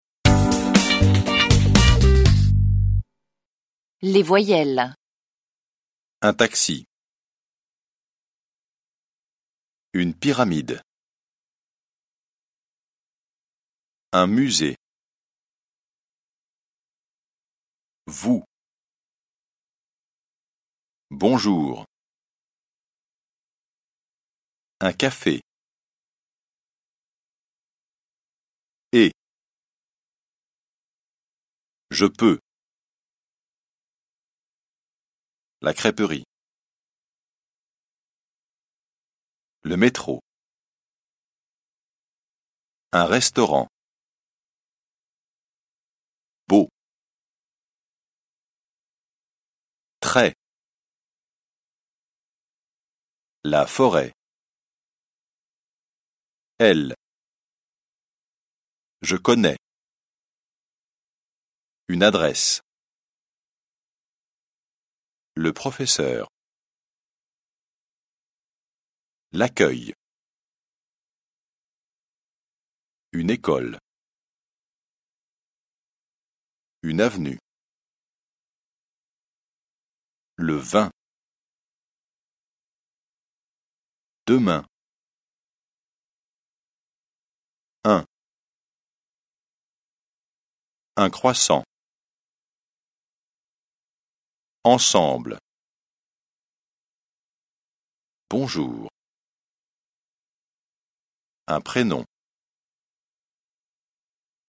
به فایل صوتی زیر چندبار گوش کنید تا با تلفظ صحیح حروف صدادار (les voyelles) و حروف بی صدا (les consonnes)